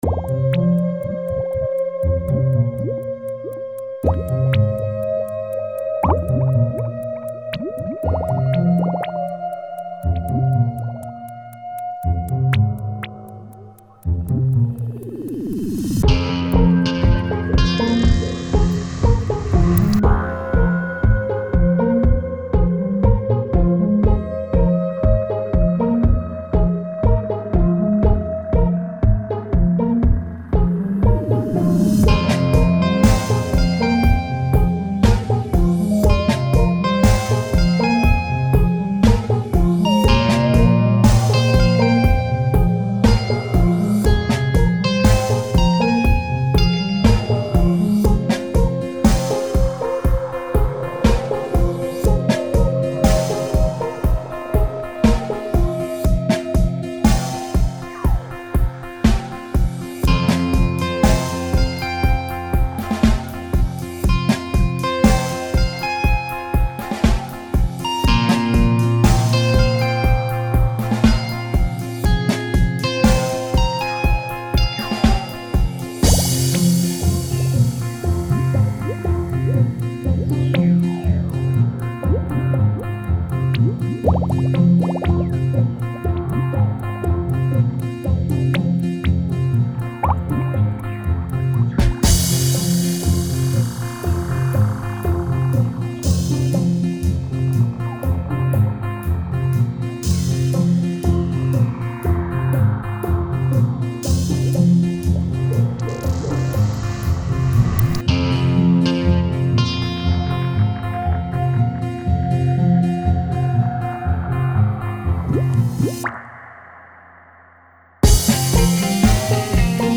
Versions musicales linéaires des sites web: